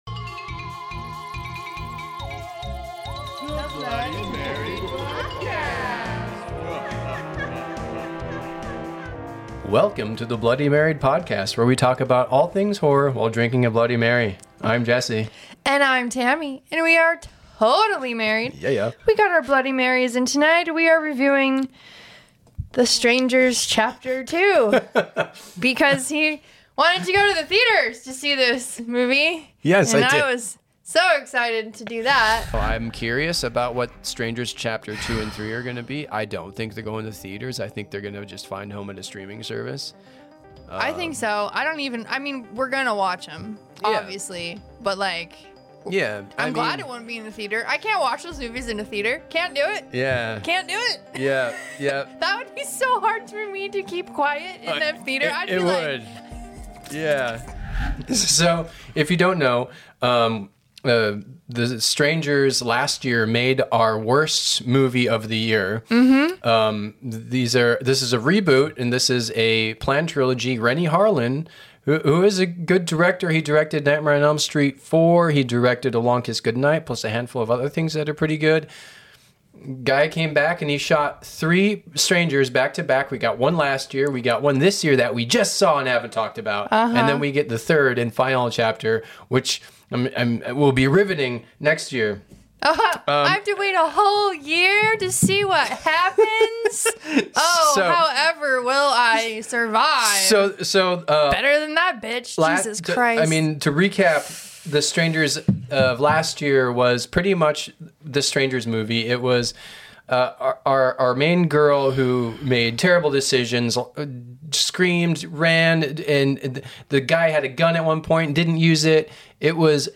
a totally rad couple, as they have a heart-to-heart about all things horror while drinking Bloody Marys.